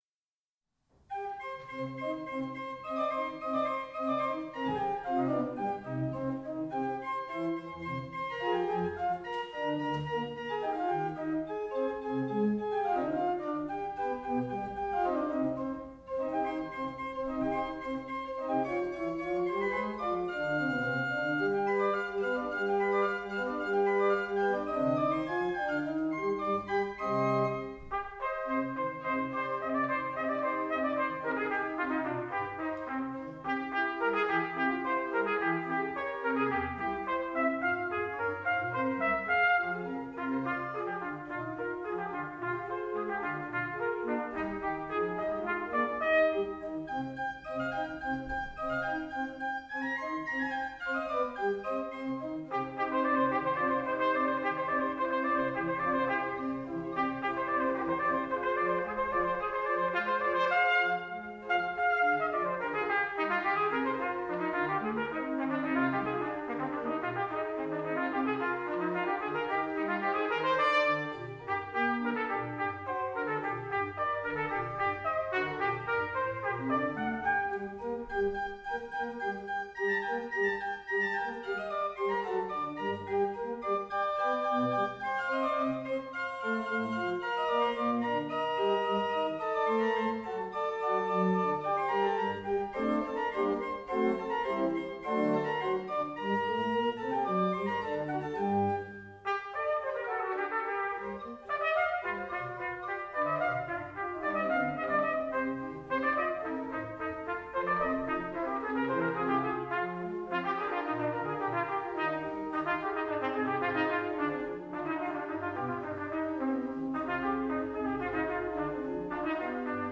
bearbeitet für Orgel und Trompete.